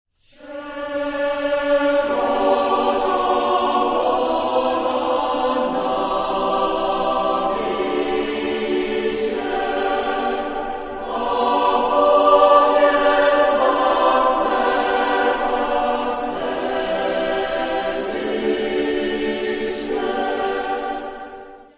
Epoque : 20ème s.
Genre-Style-Forme : Profane ; Chœur
Type de choeur : SATB  (4 voix mixtes )
Tonalité : majeur